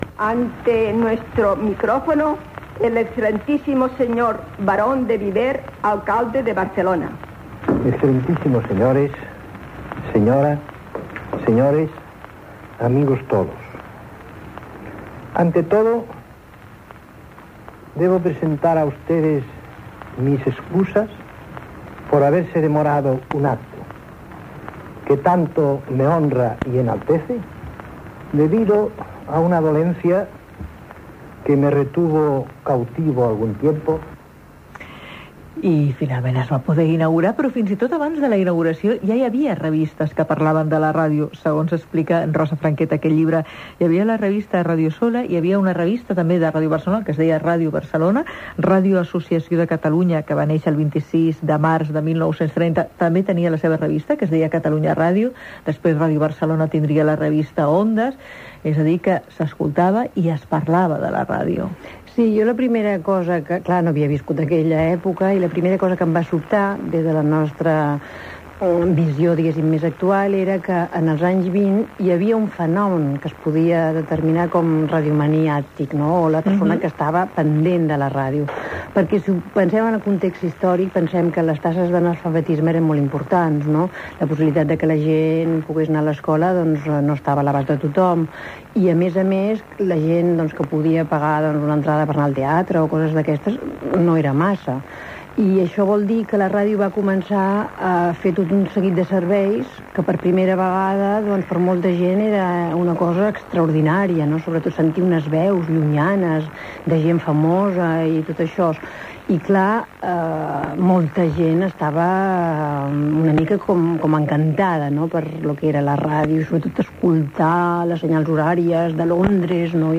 Entrevista
Paraules de Lluís Companys proclamant la primera República.